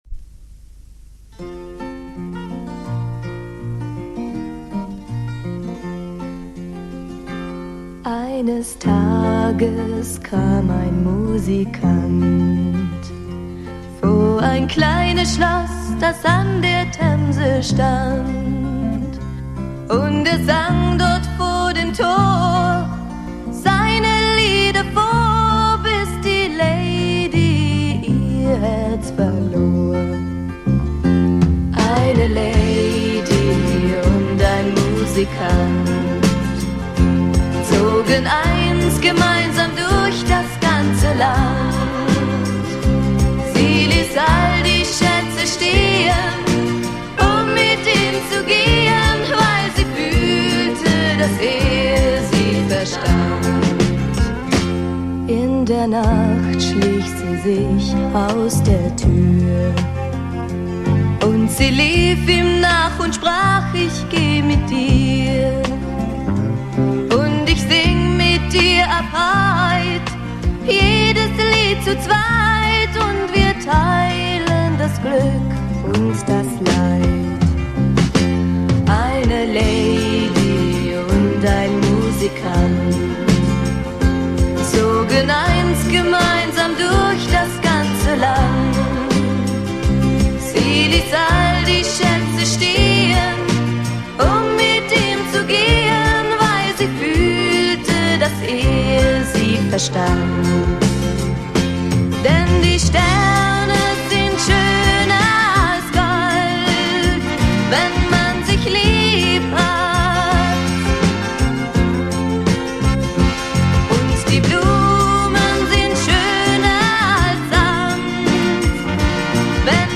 Звук положил на 2 канала, немного пошаманил, вот MP3: